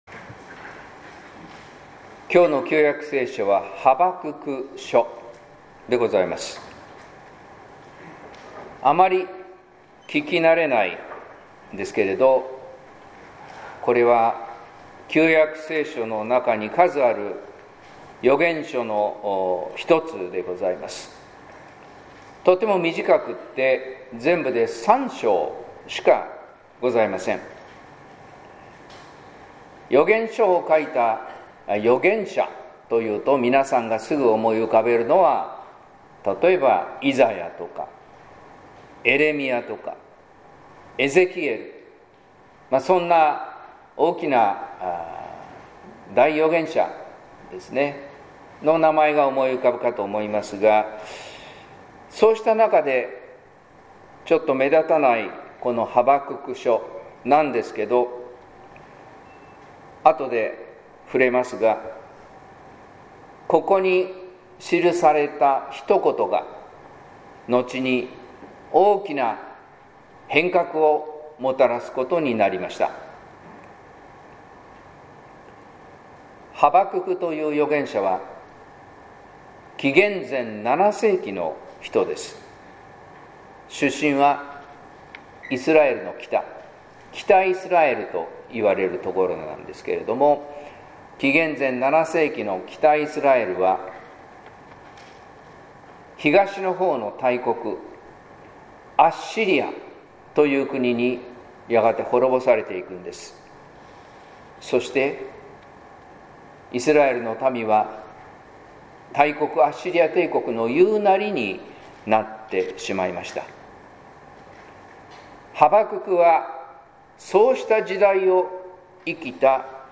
説教「からし種一粒の信仰」（音声版）